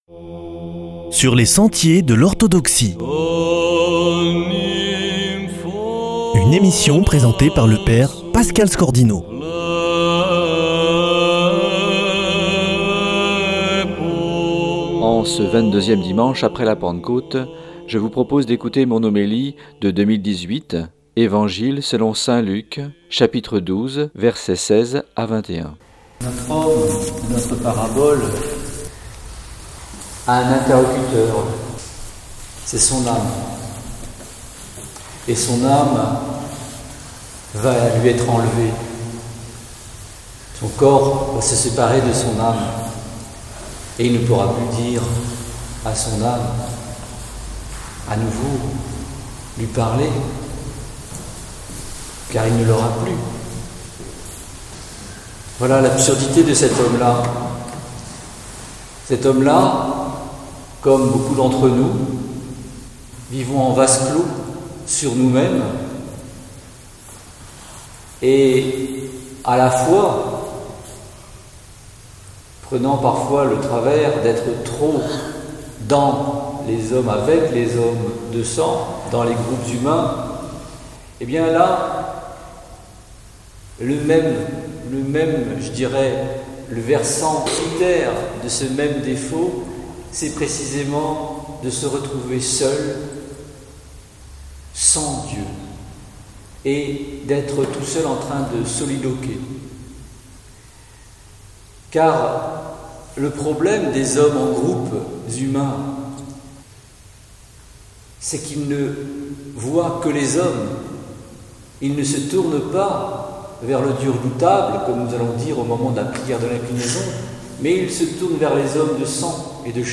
Homélie 2018 du 26e dimanche ap. Pentecôte / l'homme qui abat ses greniers